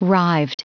Prononciation du mot rived en anglais (fichier audio)
Prononciation du mot : rived